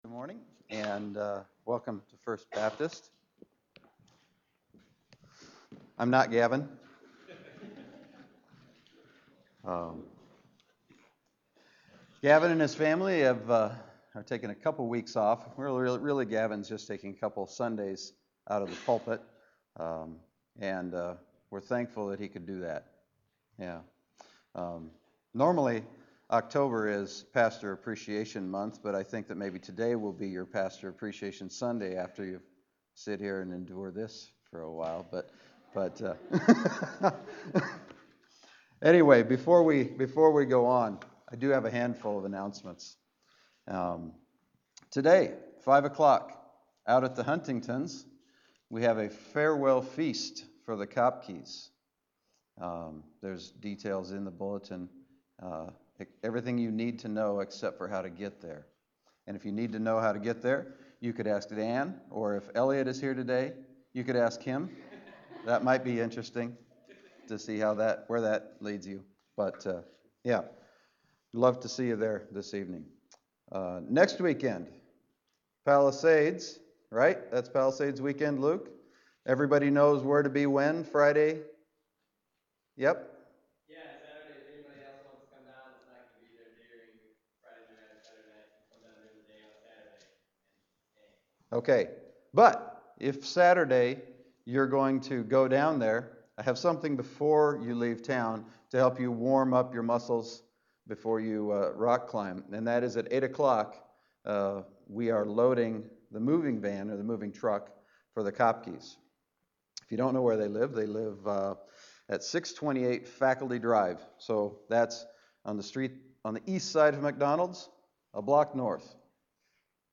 1 Corinthians 1:18-2:5 Service Type: Sunday Morning 1 Corinthians 1:18-2:5 « Do Not Disbelieve